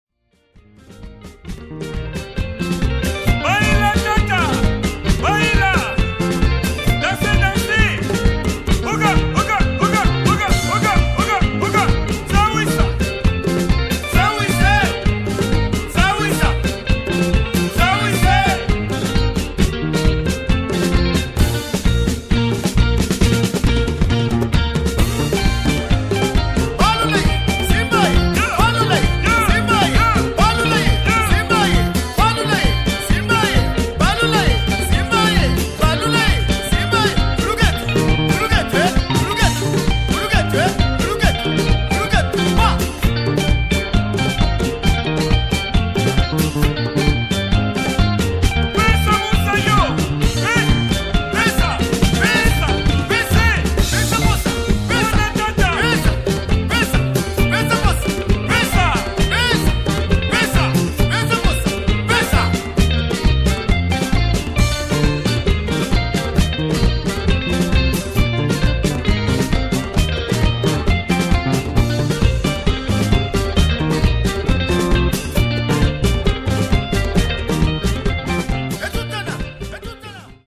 steaming soukous from the heart of the Congo!!